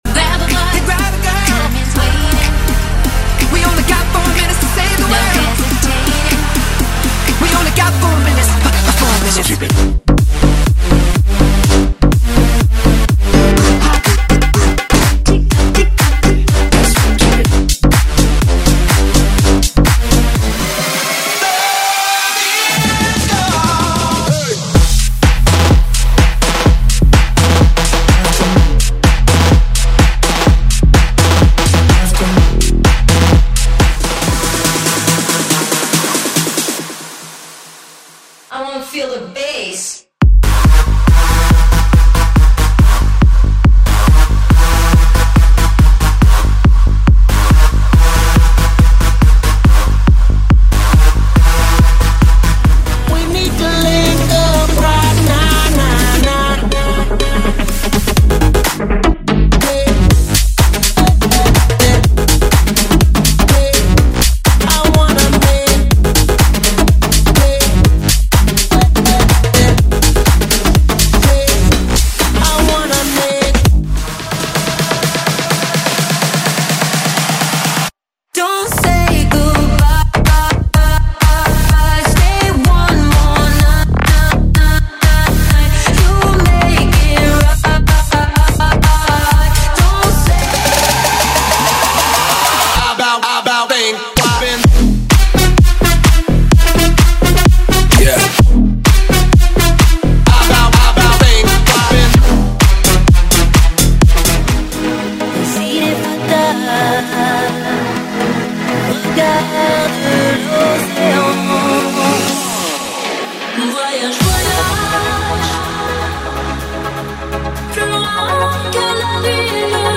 • Eletrônicas, Eletro House, Bass House, EDM = 100 Músicas
• Versões Extended
• Sem Vinhetas